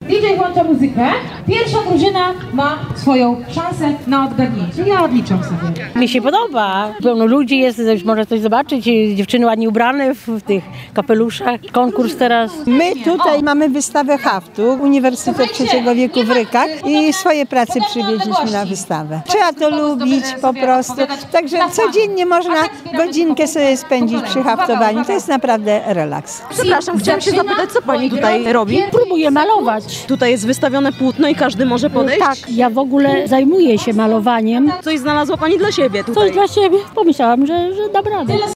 ZOBACZ ZDJĘCIA: MOSiRiada, czyli I Festiwal Aktywności Senioralnej, odbył się przy ul. Osmolickiej.